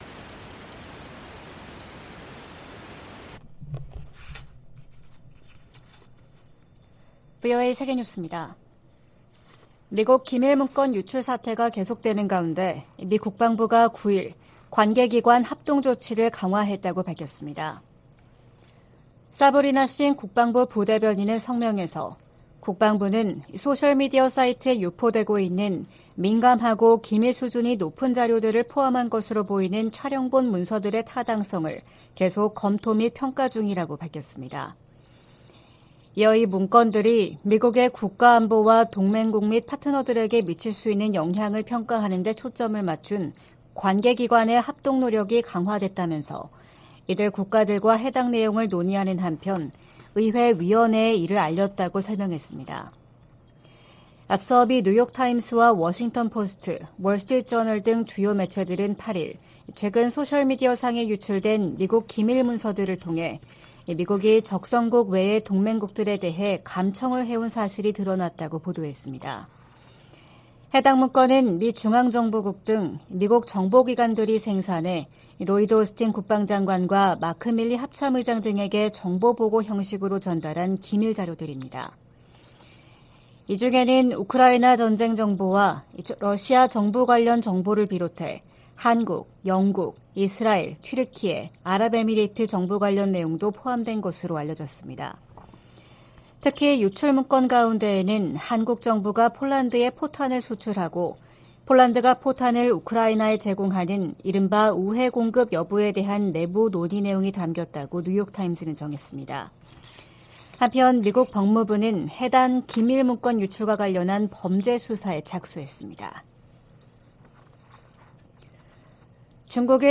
VOA 한국어 '출발 뉴스 쇼', 2023년 4월 11일 방송입니다. 한국 대통령실은 미국 정보기관의 국가안보실 감청정황 보도에 관해 "필요할 경우 미국에 합당한 조치를 요청할 것"이라고 밝혔습니다. 미국 의원들이 윤석열 한국 대통령 의회 연설 초청을 환영한다고 밝혔습니다. 북한이 '수중핵어뢰'로 알려진 핵무인 수중 공격정 '해일'의 수중 폭파시험을 또 다시 진행했다고 밝혔습니다.